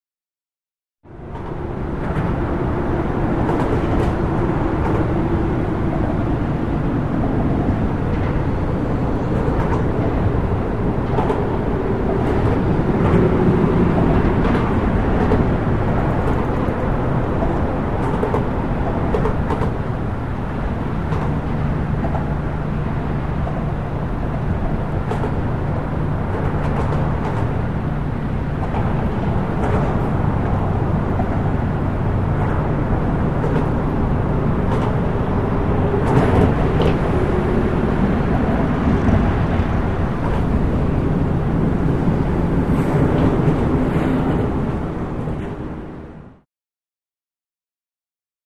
Traffic Over Bridge, Rumble With Overpass Clanks.